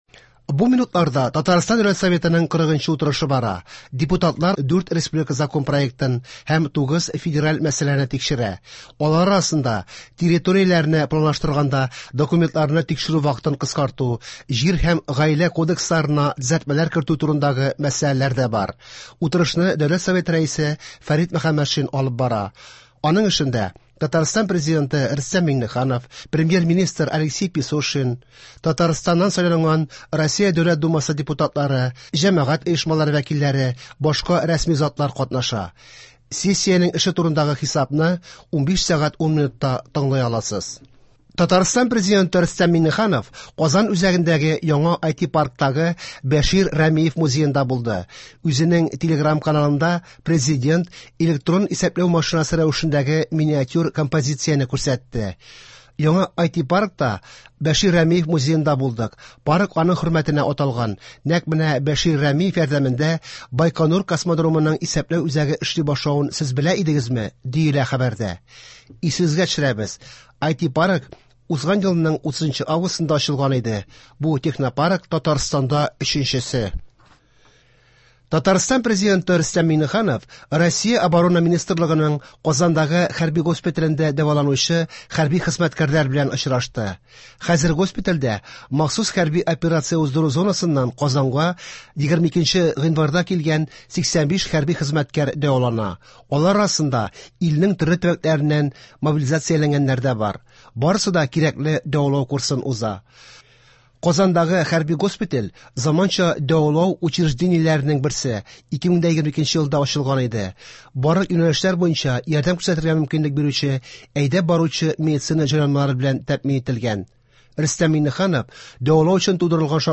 Иртәнге чыгарылыш.
Яңалыклар (26.01.23)